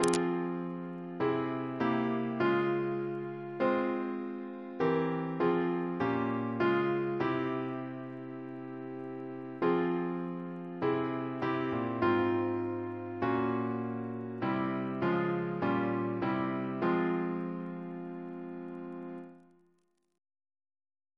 Double chant in F minor Composer: William Horsley (1774-1858) Reference psalters: PP/SNCB: 111